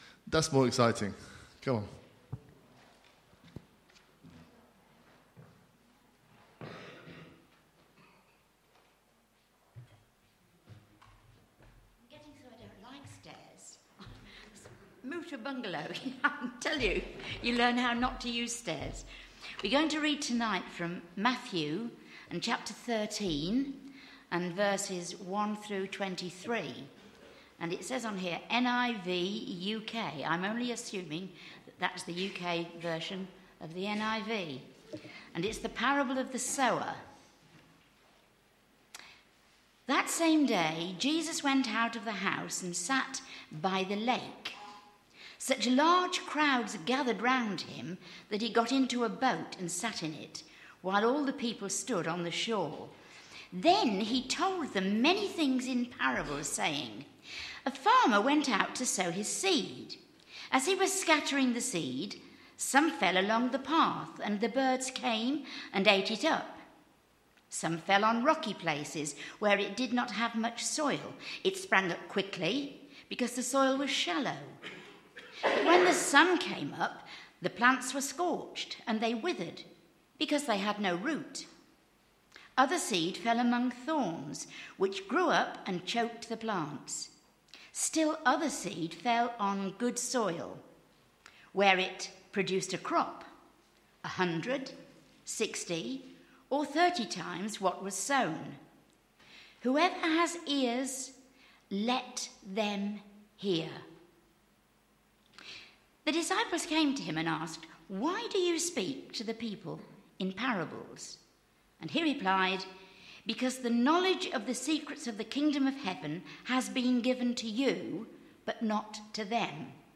A message from the series "What I love about Jesus."